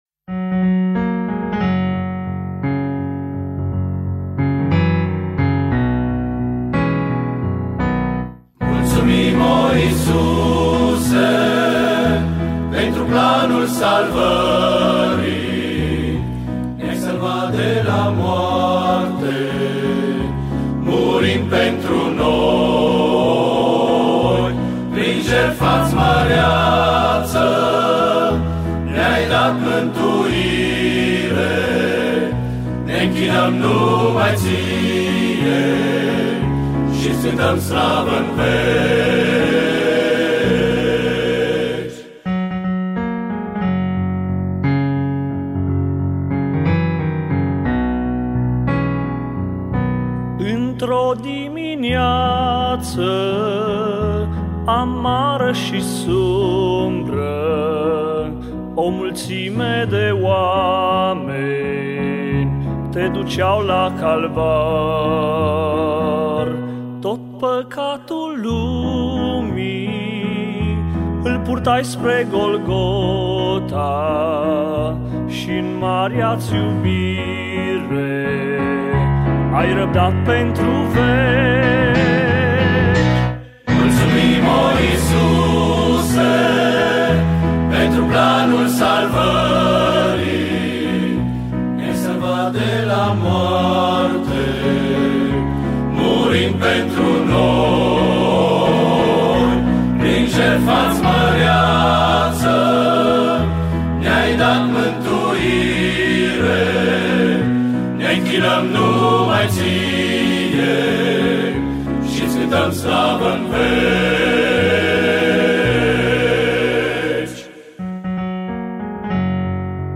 Cor bărbătesc